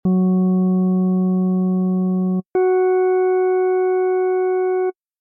On the fifth Subtractor in the pile turn the octave to 6 and the semitone to 7 – now bring the volume up like you have with the other devices.